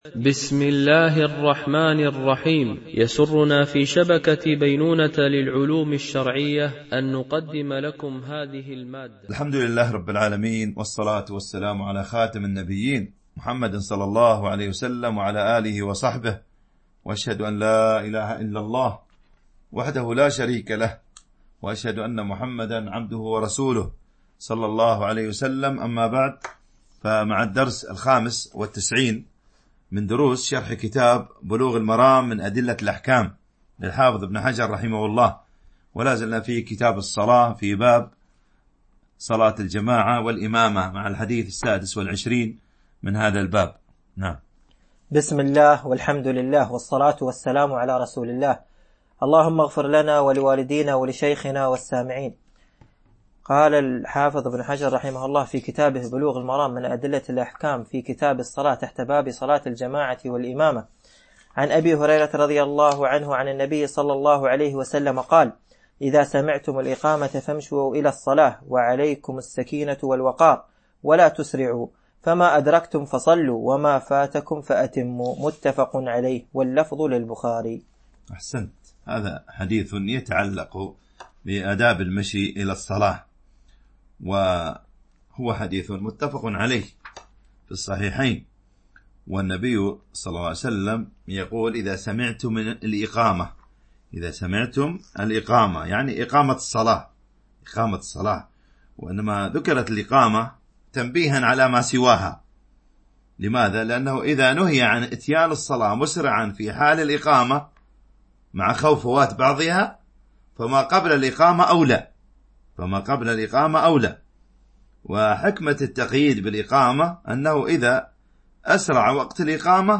شرح بلوغ المرام من أدلة الأحكام - الدرس 95 ( كتاب الصلاة - باب صلاة الجماعة - الحديث 422 - 428 )